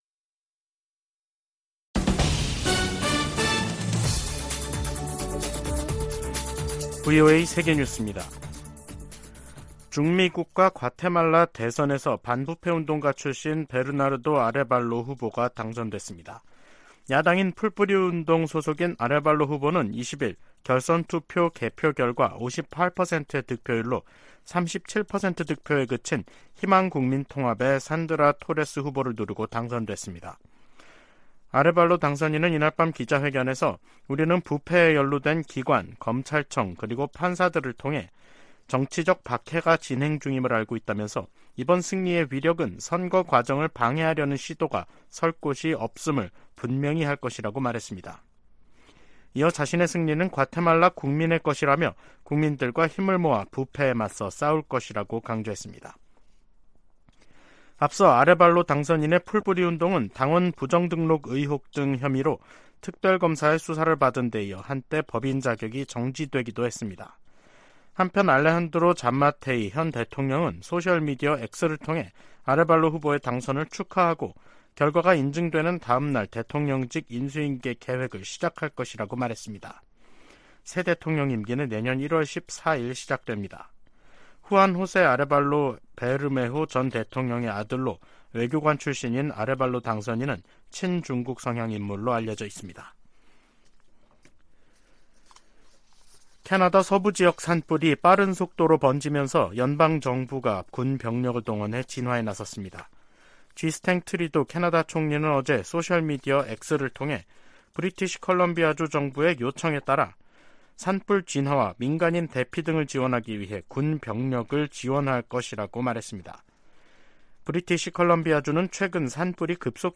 VOA 한국어 간판 뉴스 프로그램 '뉴스 투데이', 2023년 8월 21일 3부 방송입니다. 미국과 한국, 일본 정상들은 18일 채택한 캠프 데이비드 정신에서 3국 정상 회의를 연 1회 이상 개최하기로 합의했습니다. 윤석열 한국 대통령은 북한의 도발 위협이 커질수록 미한일 안보 협력은 견고해질 것이라고 밝혔습니다. 조 바이든 미국 대통령은 우크라이나에서와 같은 사태가 아시아에도 벌어질 수 있다고 경고하며 안보 협력 중요성을 강조했습니다.